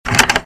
doors_door_open.ogg